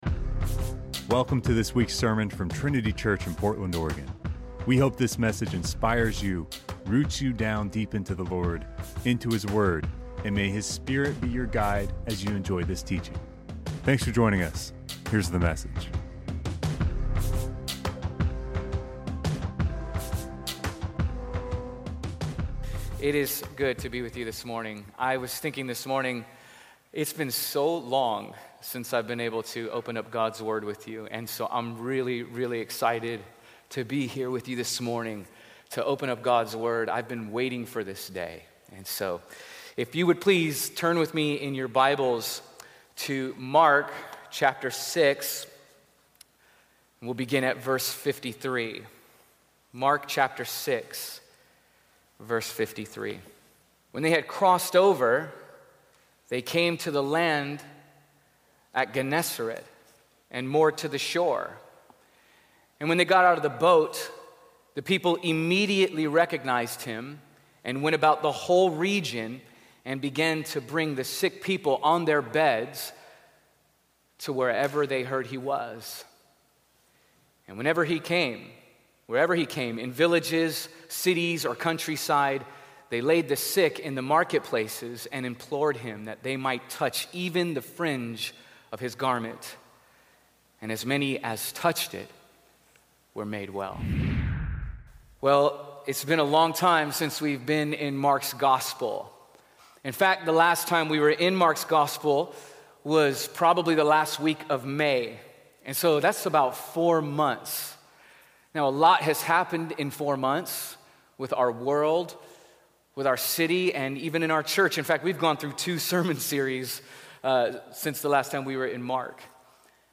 Welcome to this week’s sermon from Trinity Church in Portland, Oregon.